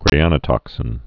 (grāănə-tŏksĭn)